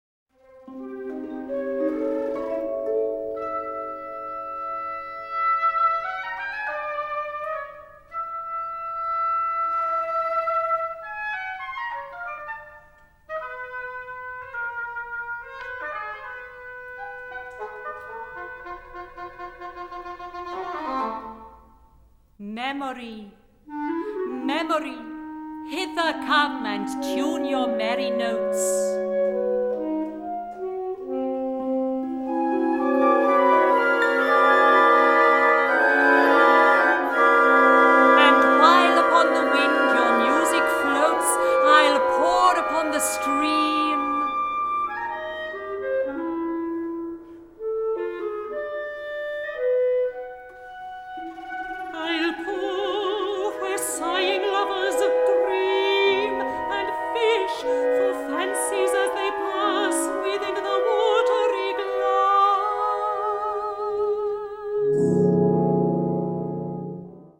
Categoria Concert/wind/brass band
Instrumentation Ha (orchestra di strumenti a faito)